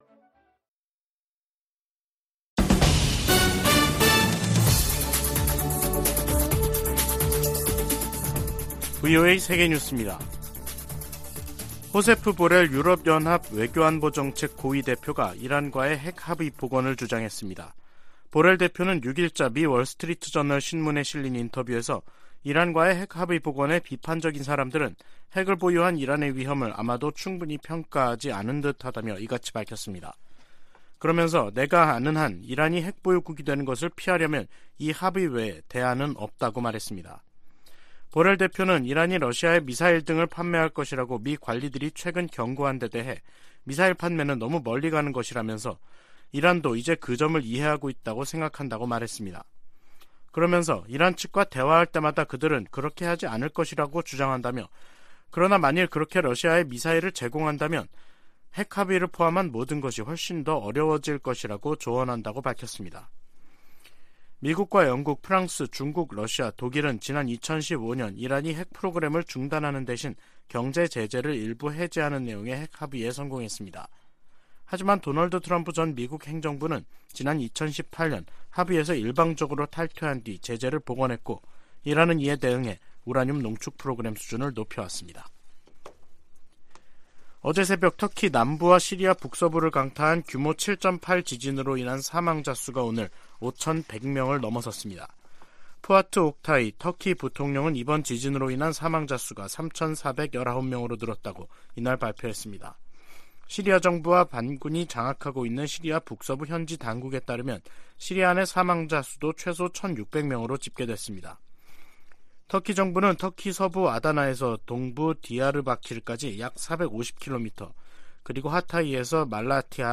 VOA 한국어 간판 뉴스 프로그램 '뉴스 투데이', 2023년 2월 7일 3부 방송입니다. 미국 백악관은 미국 상공에 정찰풍선을 띄운 중국의 행동은 용납될 수 없다면서 미중 관계 개선 여부는 중국에 달려 있다고 지적했습니다. 미 국무부는 북한의 열병식 준비 움직임을 늘 지켜보고 있으며 앞으로도 계속 주시할 것이라고 밝혔습니다.